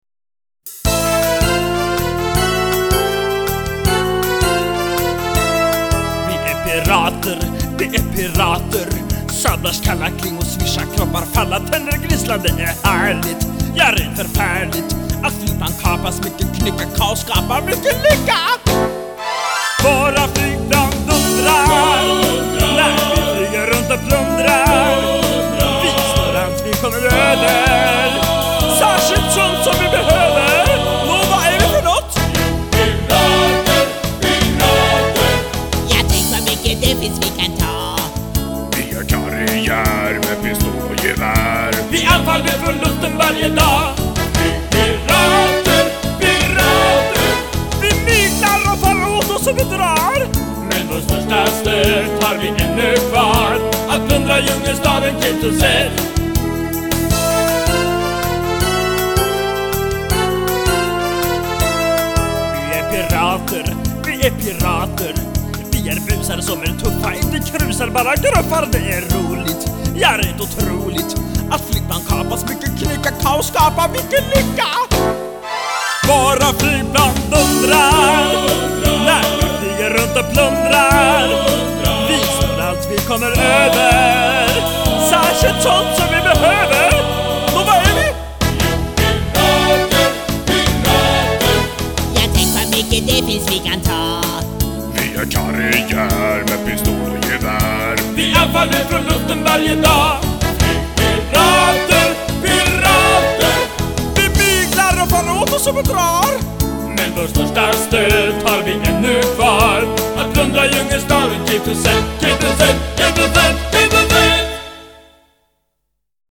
Med lite inhopp från diverse andra samt kör i bakgrunden.